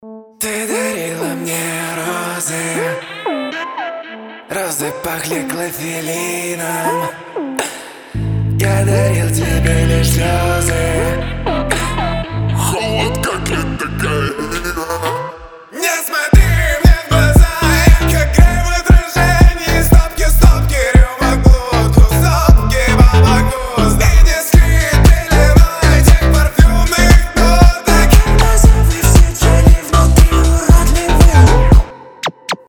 • Качество: 320, Stereo